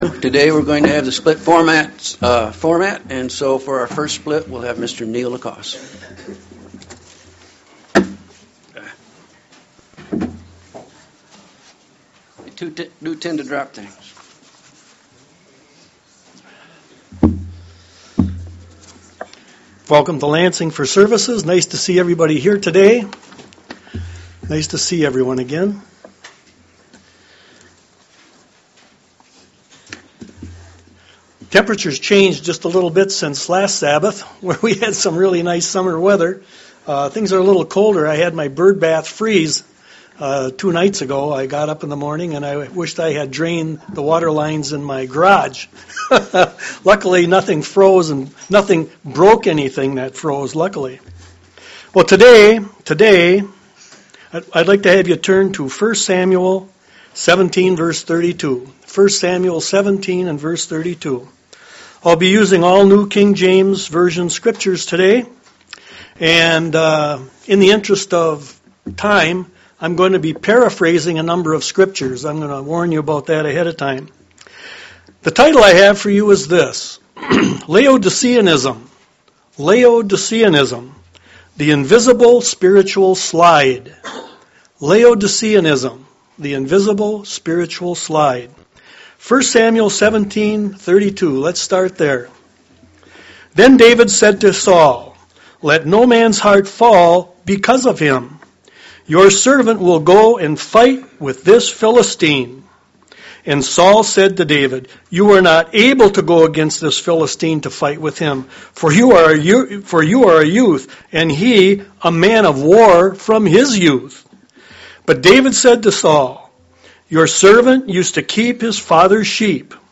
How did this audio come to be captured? Given in Lansing, MI